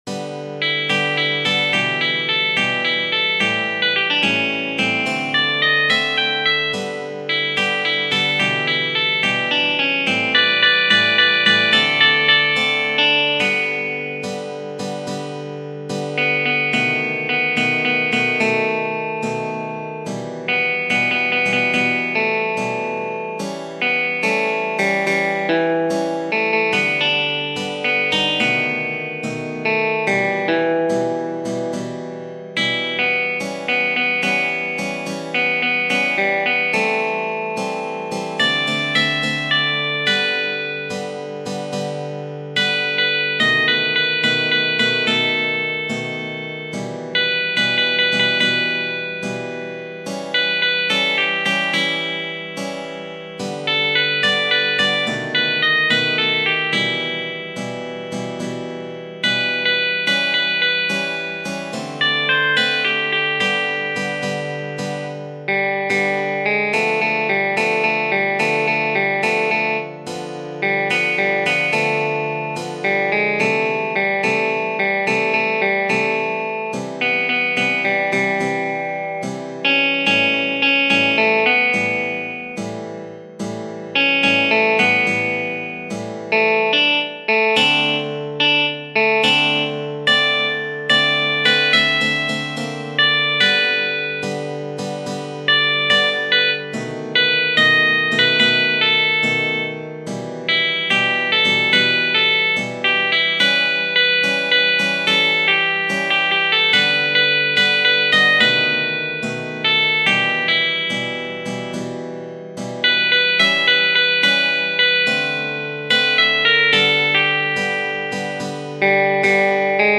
Genere: Jazz